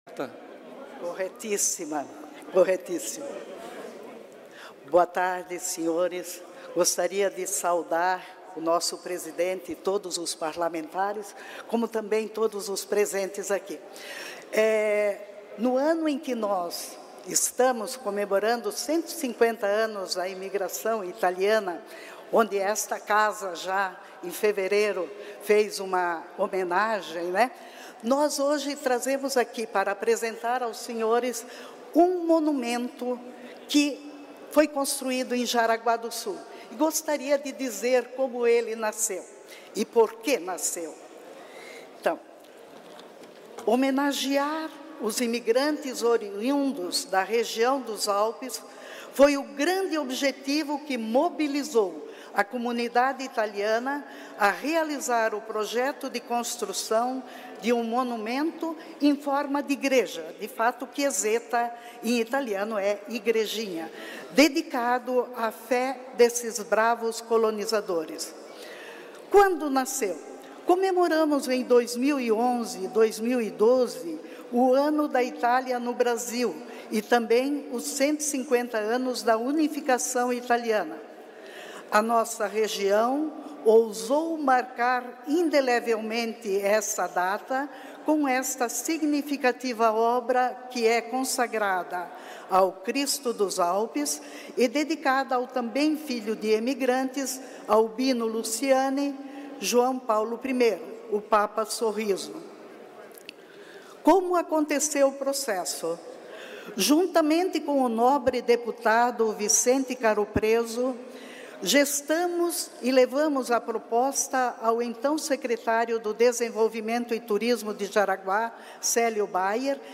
Pronunciamentos das entidades da região Norte na sessão ordinária desta terça-feira (4)